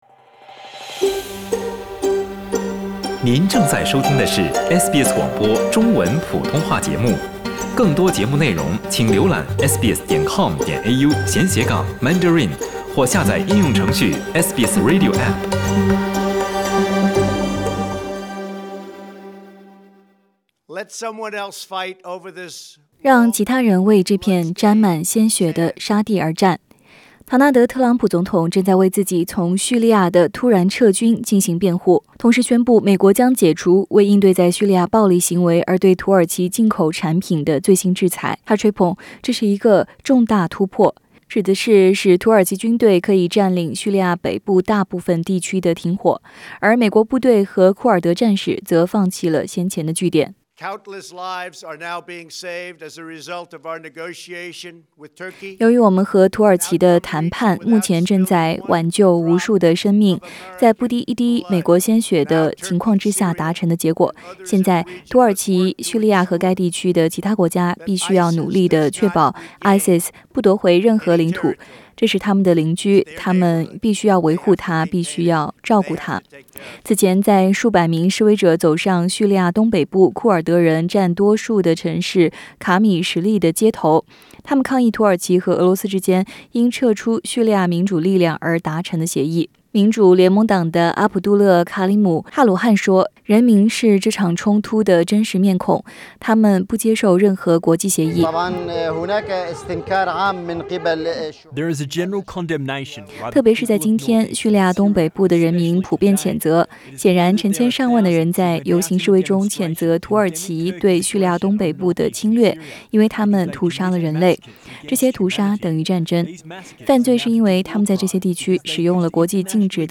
Source: AAP SBS 普通话电台 View Podcast Series Follow and Subscribe Apple Podcasts YouTube Spotify Download (5.59MB) Download the SBS Audio app Available on iOS and Android 土耳其在叙利亚北部的永久停火，促使美国总统特朗普取消最近的制裁。